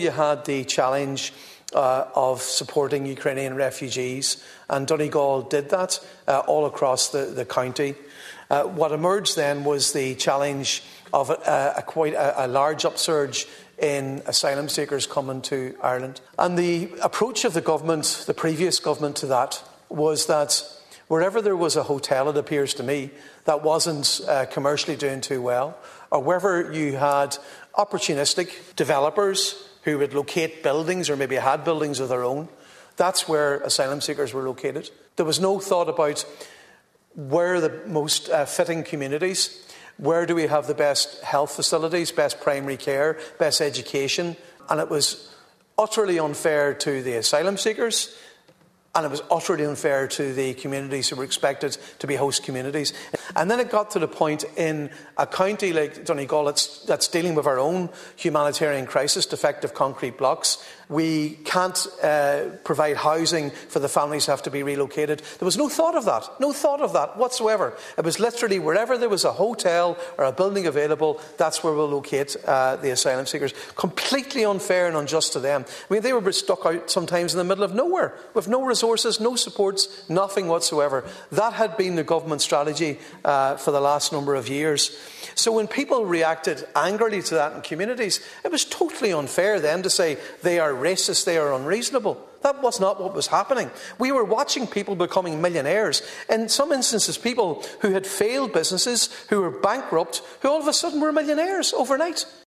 Deputy Padraig Mac Lochlainn was speaking during the second stage of the International Protection Bill.